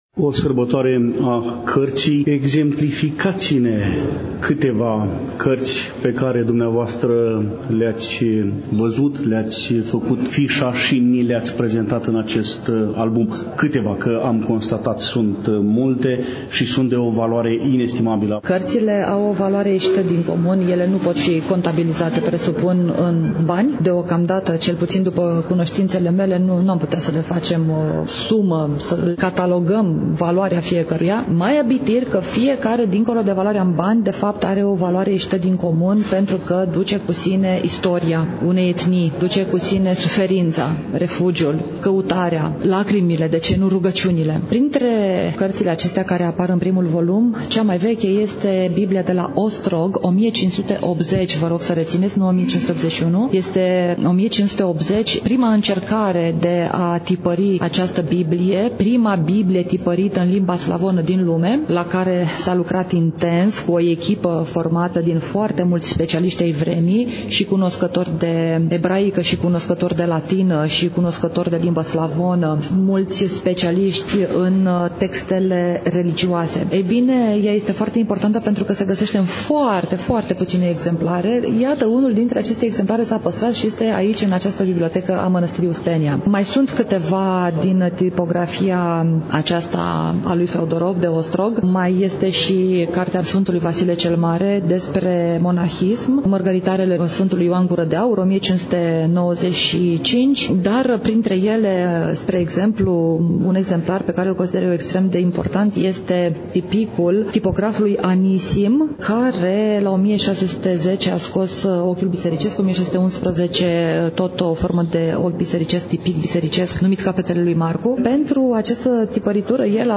Evenimentul a avut loc, nu demult, în Amfiteatrul „I. H. Rădulescu” al Bibliotecii Academiei Române, București.